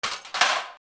古コタツの電熱部分を落とす